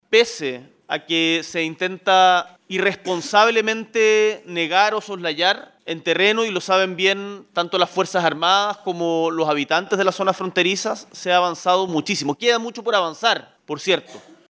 No obtante, este viernes, al inicio de su consejo de gabinete en La Moneda, el Mandatario volvió abordar las críticas, asegurando que sí se ha avanzado en el control de la frontera.